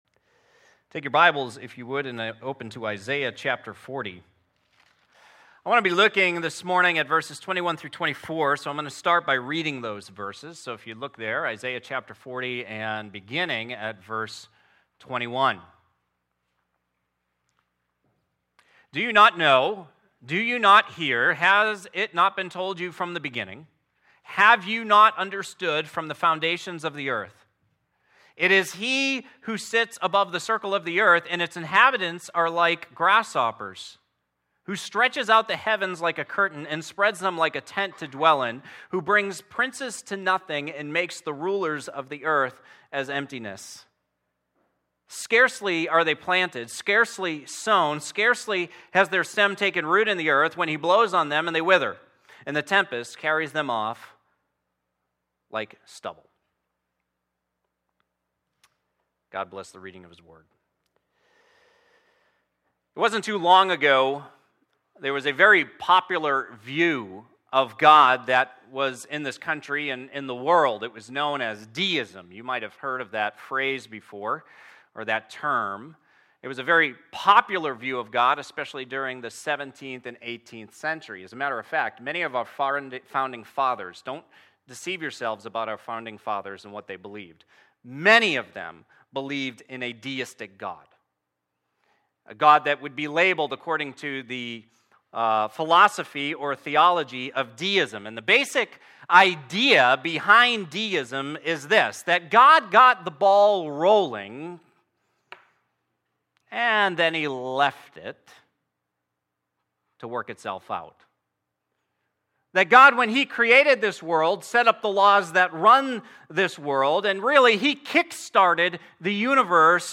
Sermons | Gospel Life Church